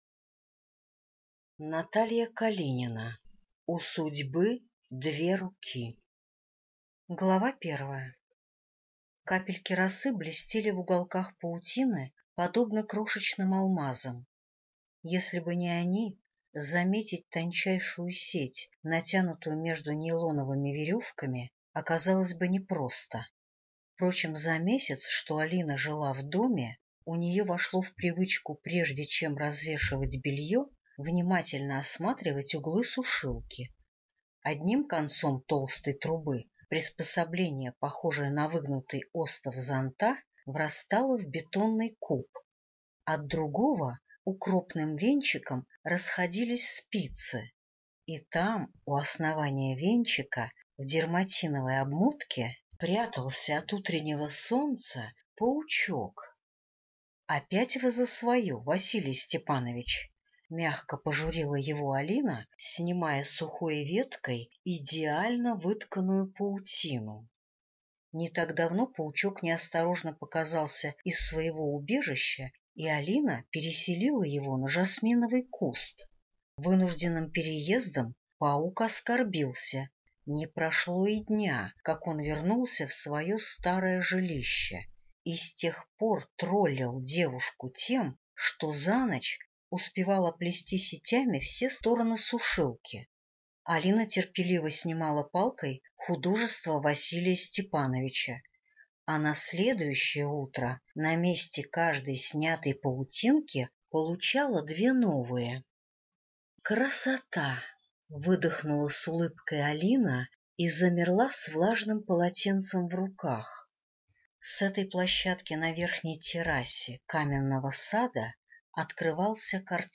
Аудиокнига У судьбы две руки | Библиотека аудиокниг